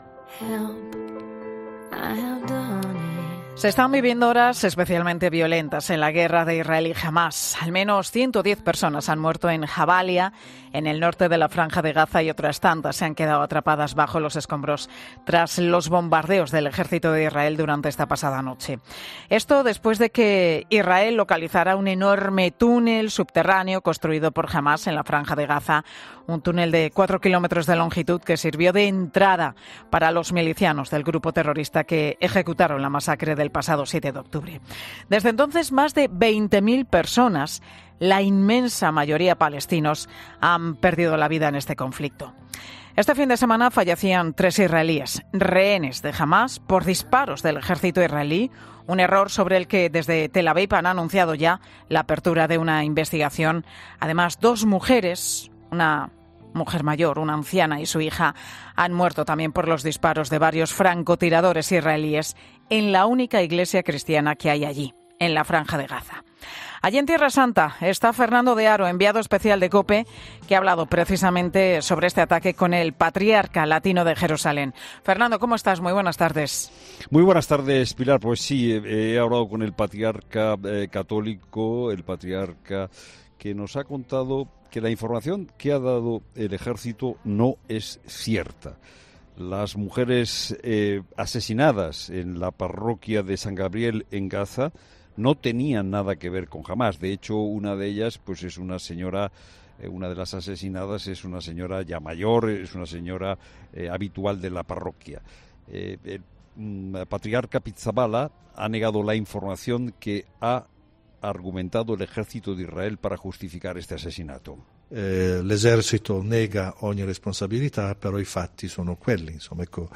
La Tarde sigue emitiéndose este martes desde Tierra Santa.
Esta mañana hemos estado en Cisjordania -que es territorio palestino-, donde el clima es mucho más tenso.